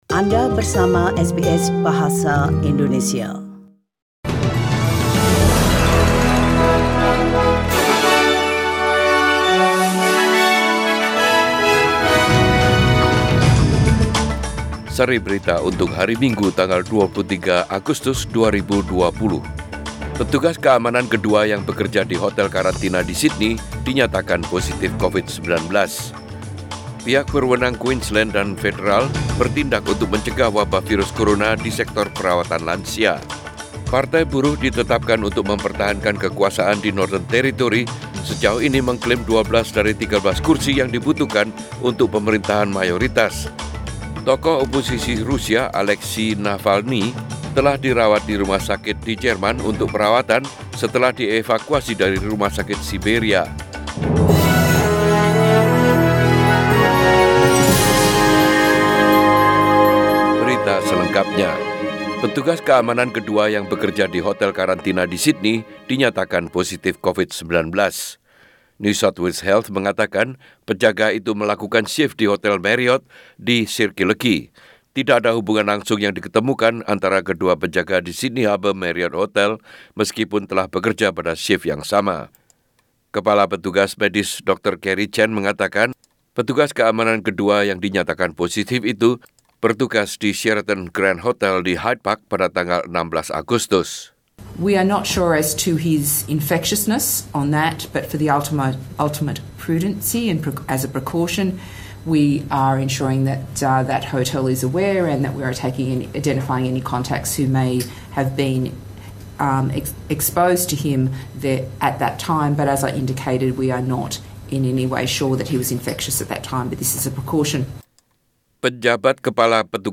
SBS Radio News in Bahasa Indonesia - 23 August 2020